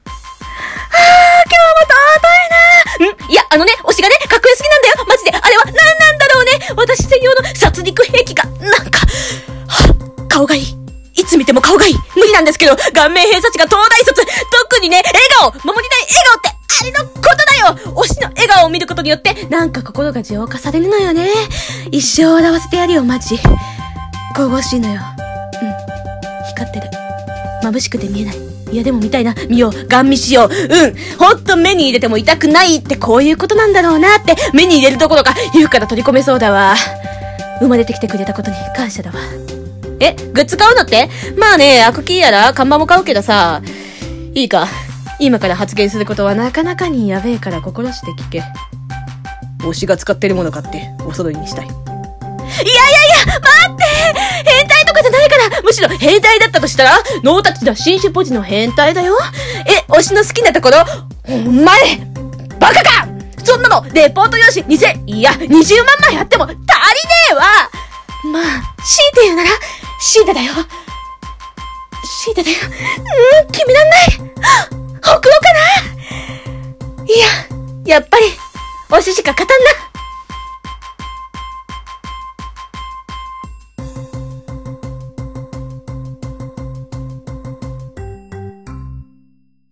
【声劇】 推ししか勝たん